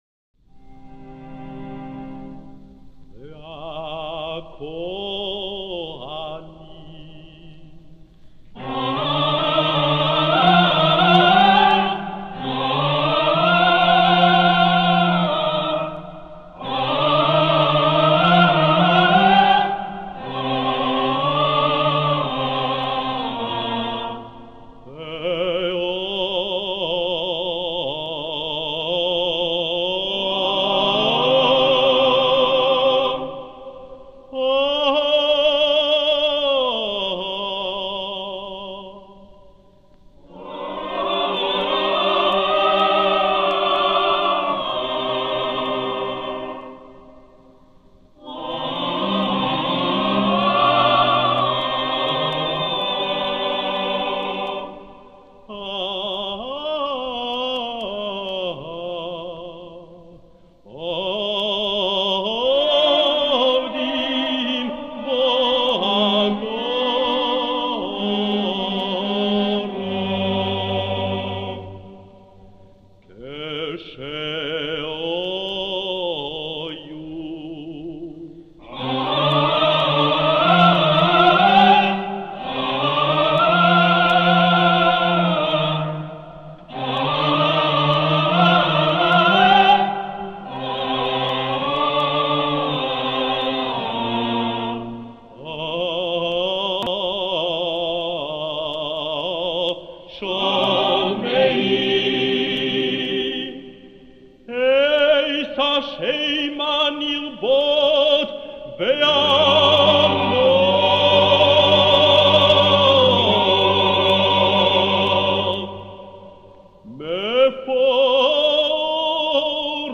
Musiques liturgiques de la synagogue de la Victoire
Avec la présence d’un chœur imposant, de deux orgues (un portatif et un grand orgue) et de ministres-officiants aux voix puissantes, la musique s’affirme comme un élément essentiel du culte israélite français dont le temple de la Victoire est l’étendard.